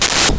explosion3.wav